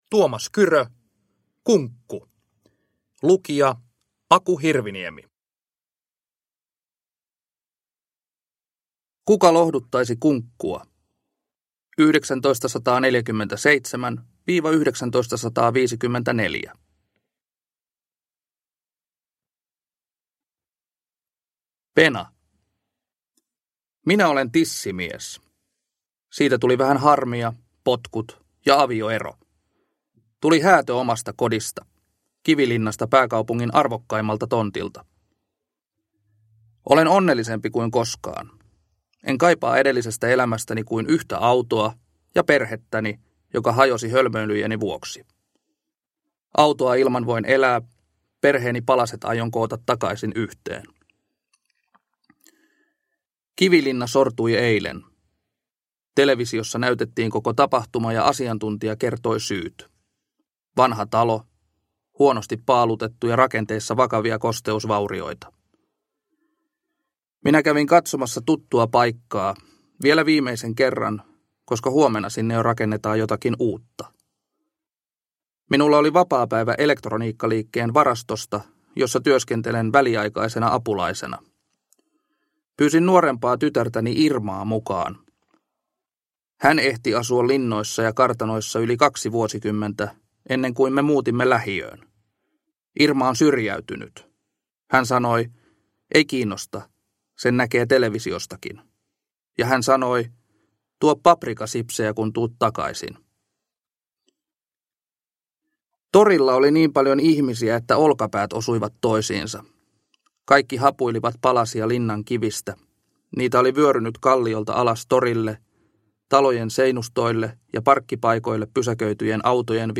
Kunkku (ljudbok) av Tuomas Kyrö